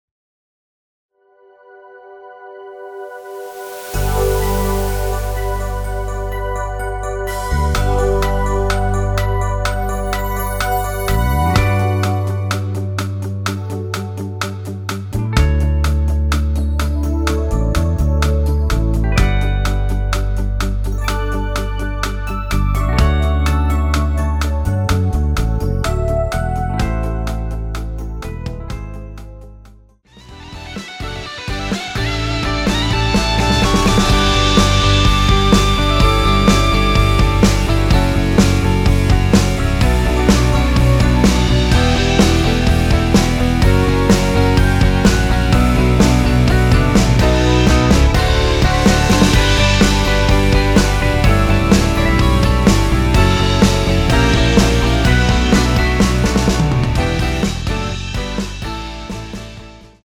원키에서(+5)올린 MR입니다.
앞부분30초, 뒷부분30초씩 편집해서 올려 드리고 있습니다.